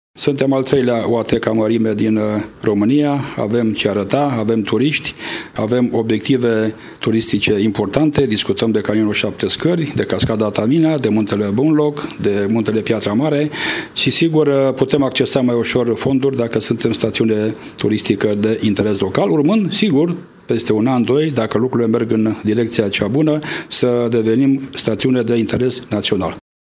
Virgil Popa a mai declarat că a demarat procedura pentru ca Municipiul Săcele să devină, anul acesta, staţiune turistică de interes local.